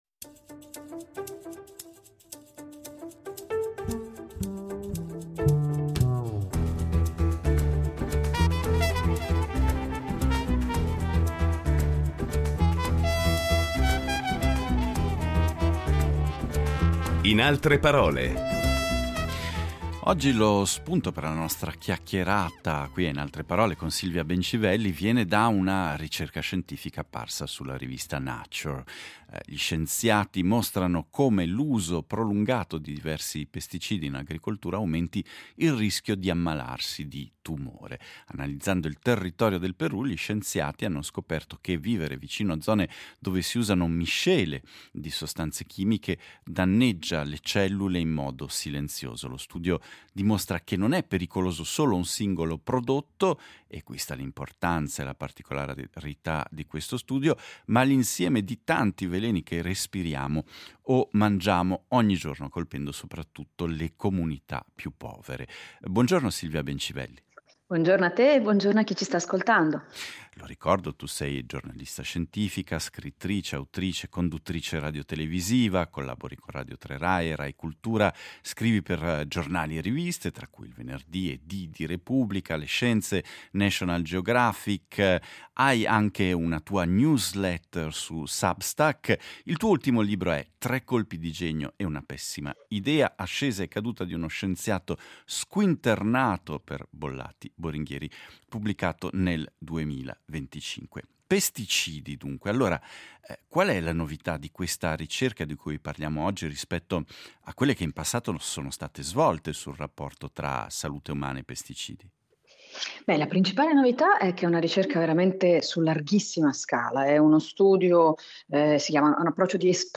Incontro con la giornalista scientifica, scrittrice, autrice e conduttrice radiotelevisiva